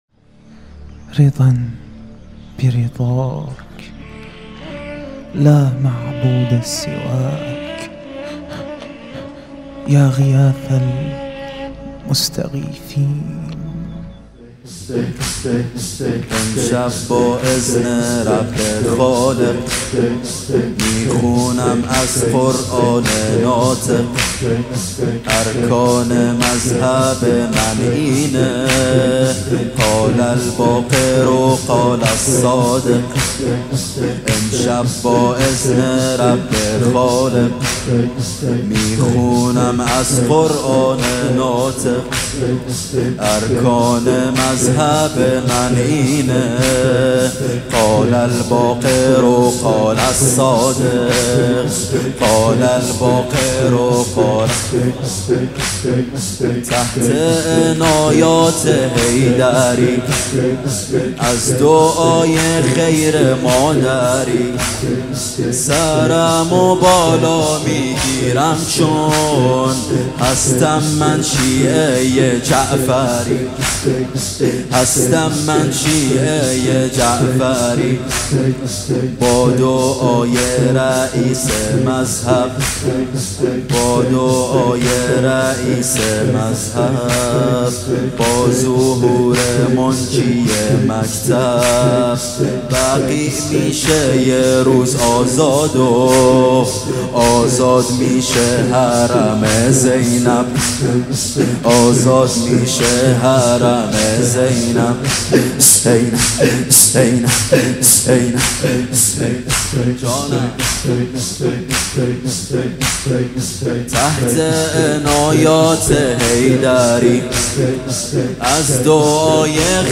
زمینه | امشب با اذن رب خالق، می‌خونم از قرآن ناطق
مداحی
شهادت امام جعفر صادق(ع)
هیأت علی اکبر بحرین